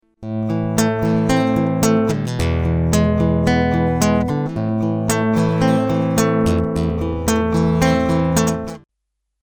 В результате купил относительно не дорогую Cort Sunset-Nylectric.
Тот" тембр в наличии есть, только звукосниматель у этой модели не полифонический, что не дает полностью использовать потенциал гитарного синтезатора.